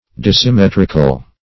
Search Result for " dissymmetrical" : The Collaborative International Dictionary of English v.0.48: Dissymmetrical \Dis`sym*met"ric*al\, a. Not having symmetry; asymmetrical; unsymmetrical.